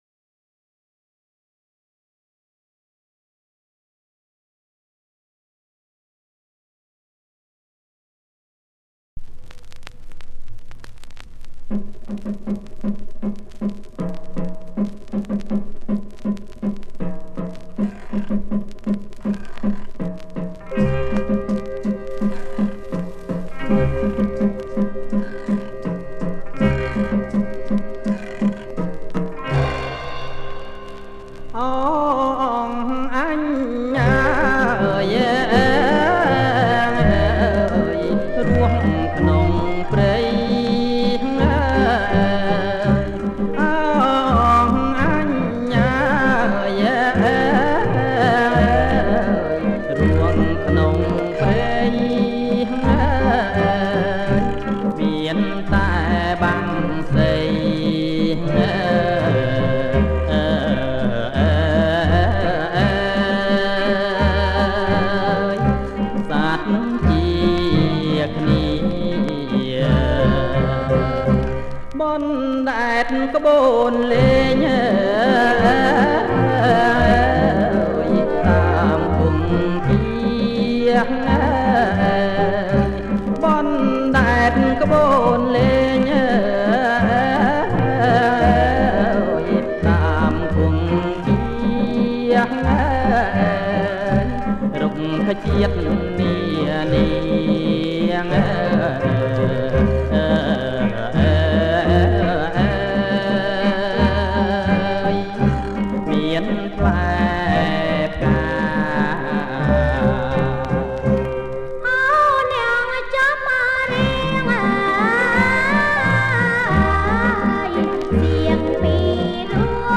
ថតផ្ទាល់ពីថាស